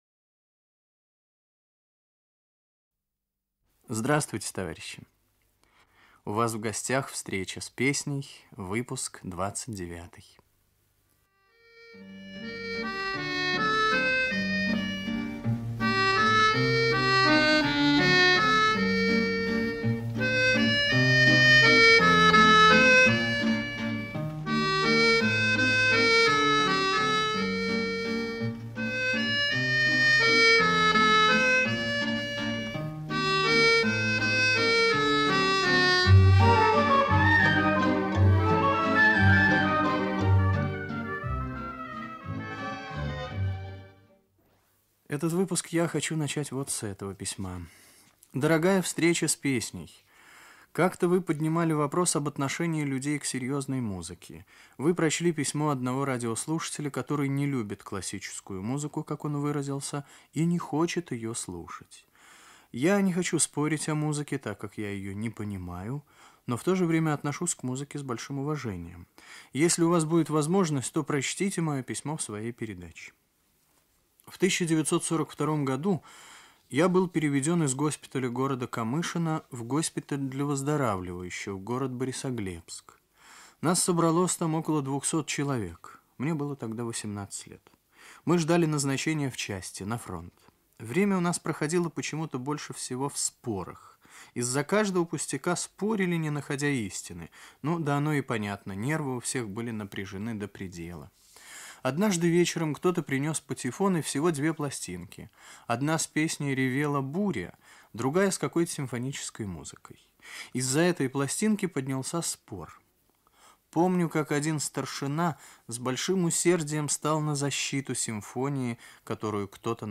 Русская революционная песня.
Старинный русский романс
Оркестр 2. Хор (Без сопровождения) 3.